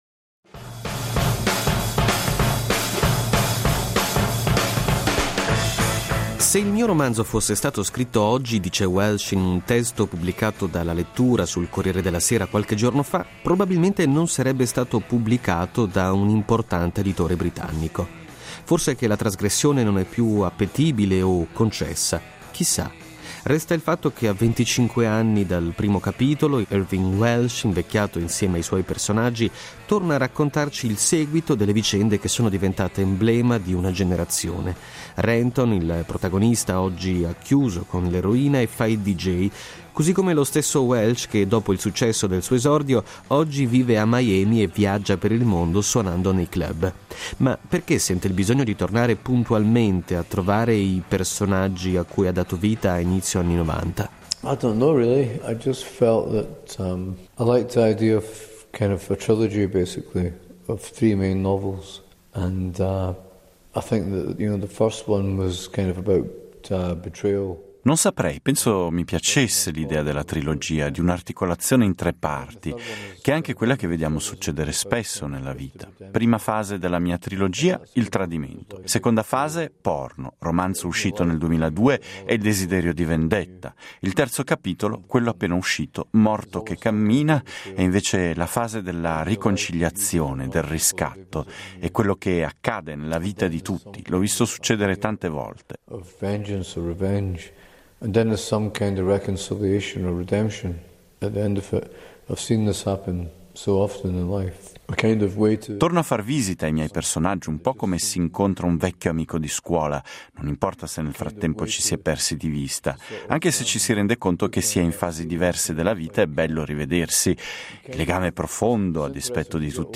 Era uno degli ospiti più attesi di Babel, il Festival di letteratura e traduzione la cui 14esima edizione si è conclusa domenica 15 settembre a Bellinzona. Irvine Welsh, dal Palco del Teatro Sociale ha ripercorso la genesi del libro che lo ha reso celebre nel mondo, “Trainspotting”, libro che assieme al film di Danny Boyle, ispirato al suo romanzo, ha segnato gli anni ’90.